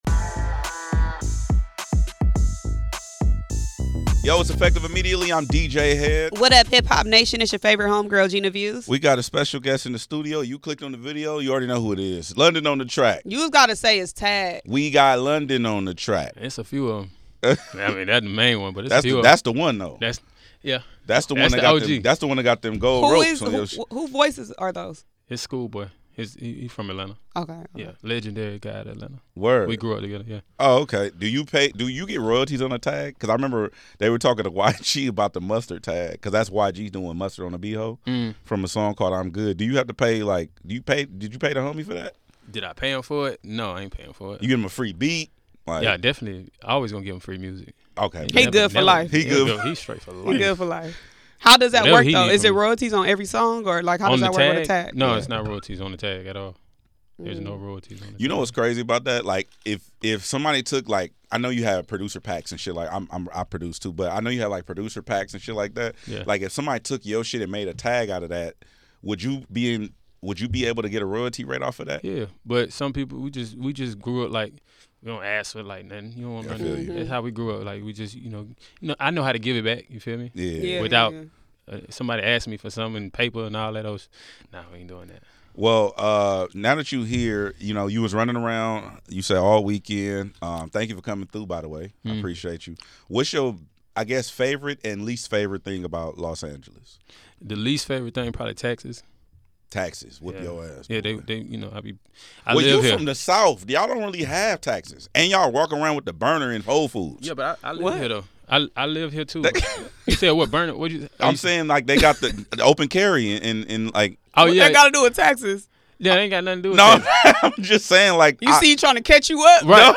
Effective Immediately is a nationally syndicated radio show and podcast that serves as the ultimate destination for cultural conversations, exclusive interviews, and relevant content. Hosted by radio and television veteran DJ Hed and new media superstar Gina Views, the show is dedicated to injecting integrity and authenticity back into the media landscape.